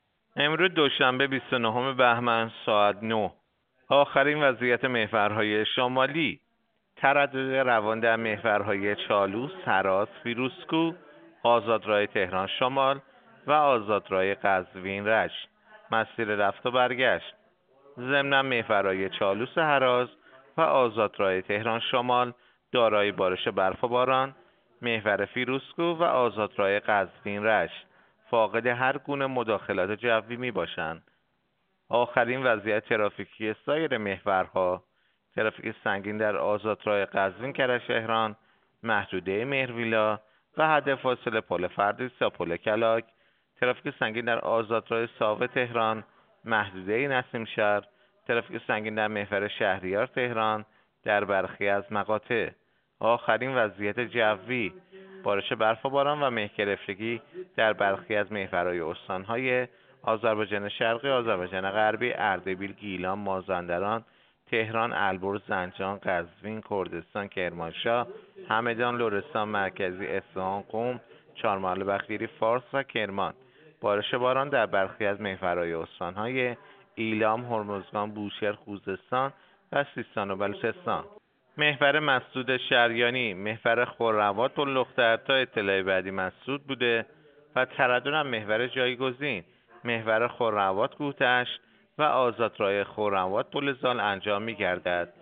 گزارش رادیو اینترنتی از آخرین وضعیت ترافیکی جاده‌ها ساعت ۹ بیست و نهم بهمن؛